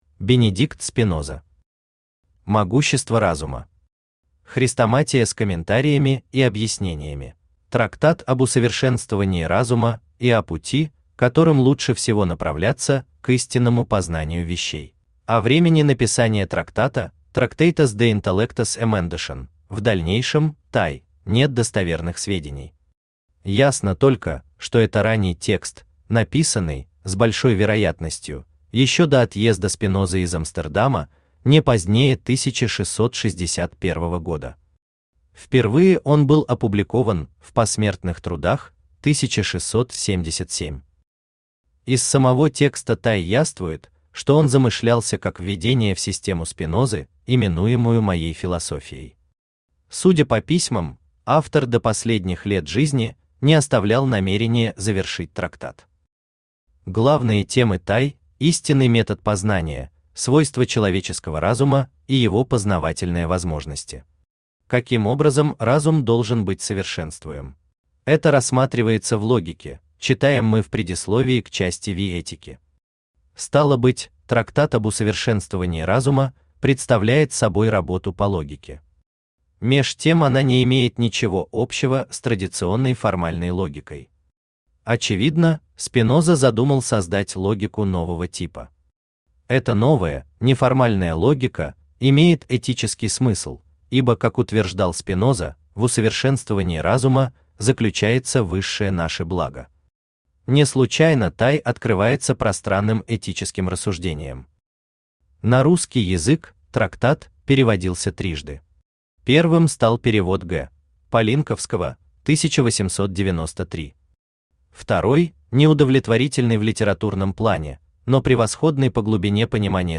Аудиокнига Могущество разума. Хрестоматия с комментариями и объяснениями | Библиотека аудиокниг
Хрестоматия с комментариями и объяснениями Автор Бенедикт Спиноза Читает аудиокнигу Авточтец ЛитРес.